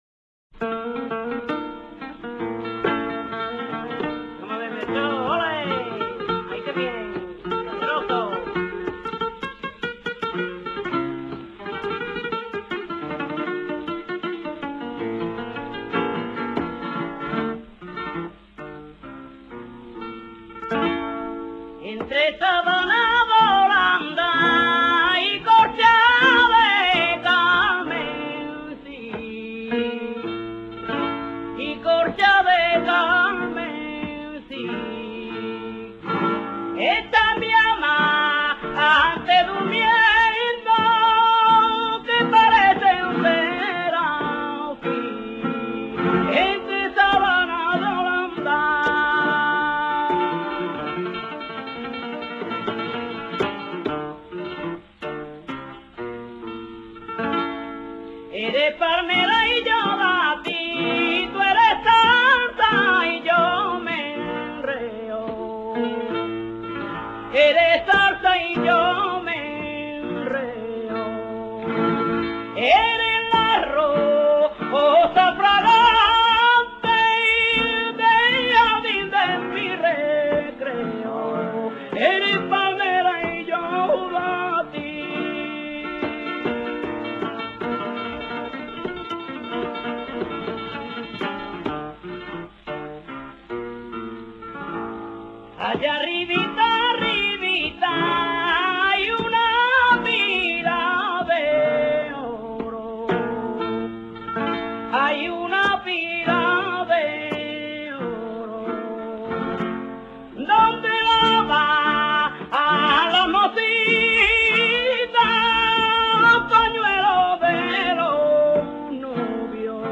Sonidos y Palos del Flamenco
bambera.mp3